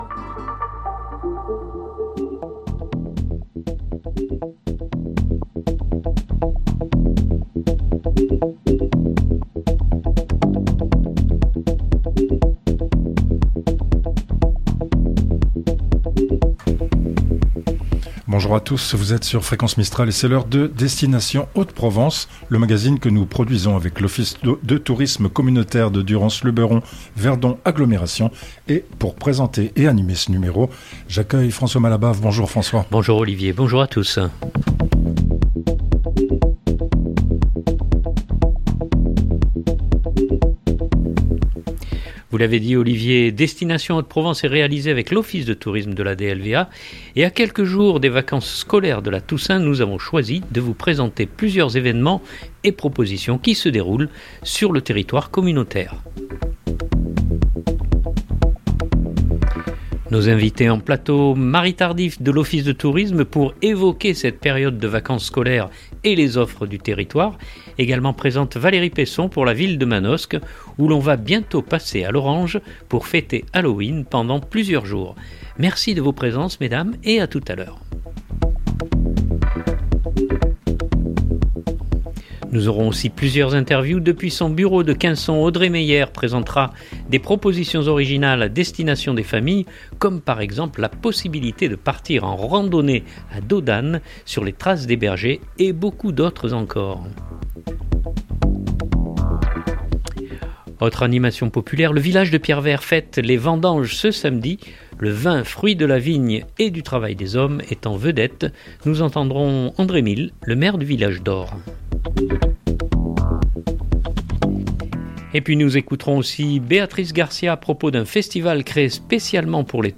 Nous aurons aussi plusieurs interviews : depuis son bureau de Quinson
Autre animation populaire : le village de Pierrevert fête les vendanges ce samedi. Le vin, fruit de la vigne et du travail des hommes est en vedette, nous entendrons André Mille, le maire du Village d’Or.